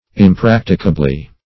\Im*prac"ti*ca*bly\
impracticably.mp3